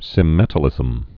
(sĭm-mĕtl-ĭzəm)